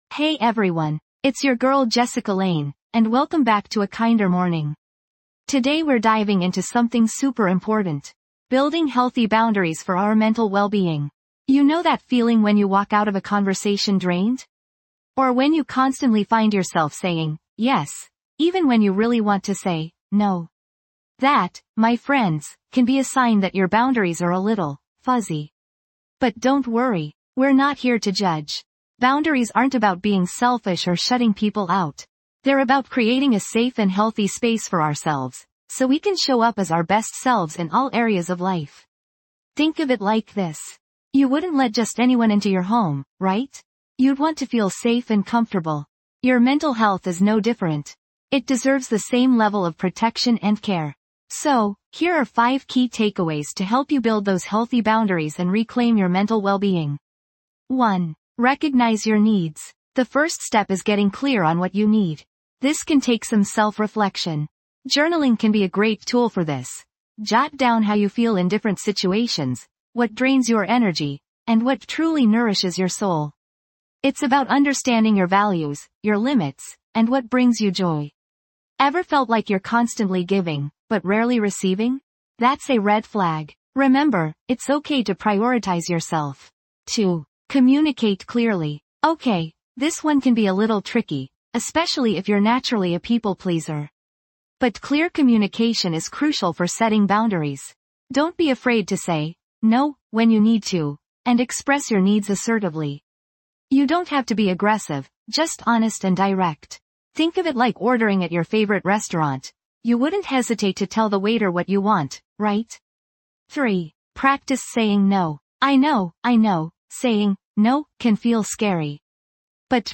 'A Kinder Morning | Compassionate Self Talk' offers guided meditations and encouraging affirmations designed to cultivate a kinder inner voice. Through daily practices, you'll learn to challenge negative thoughts, replace them with self-love and acceptance, and build resilience in the face of life's challenges.